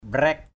/ɓrɛ:k/ mbraik =OK [Cam M] 1.